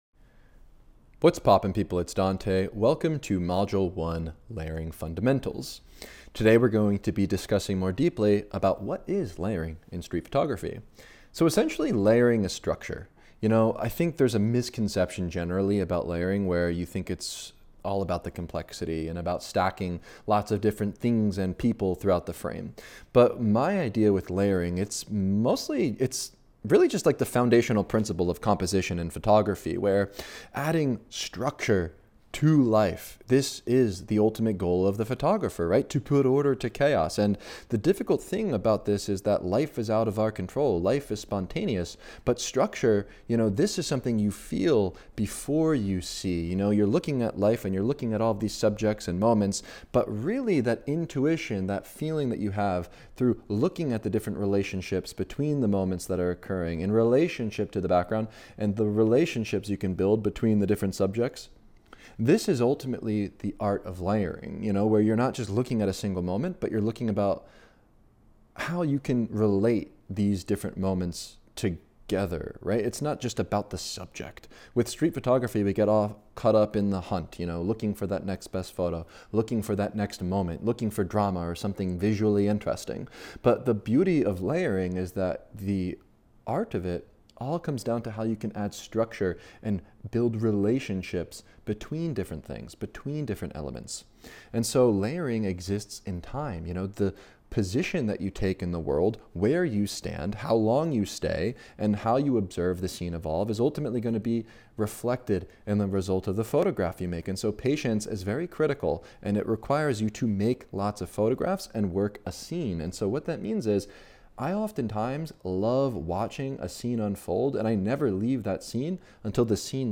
This lecture establishes the structural foundation of layering in street photography.